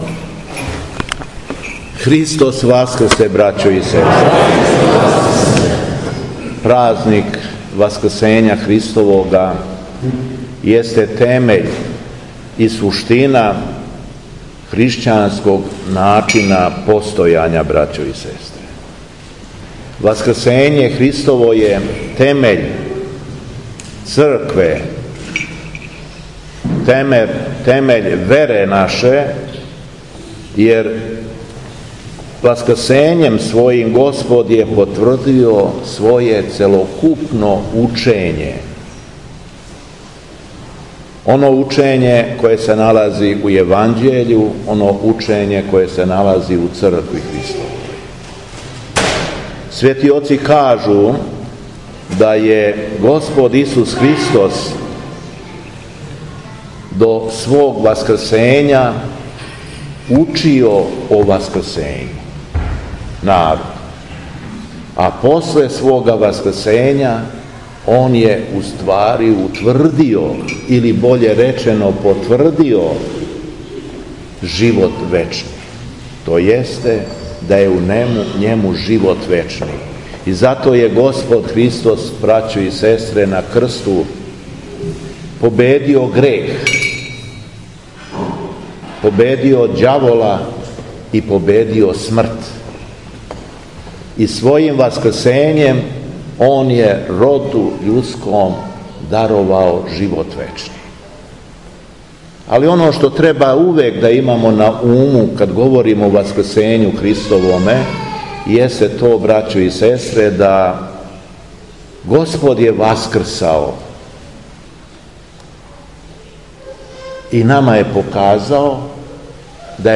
ХРАМОВНА СЛАВА У БАРАЈЕВУ - Епархија Шумадијска
Беседа Његовог Преосвештенства Епископа шумадијског г. Јована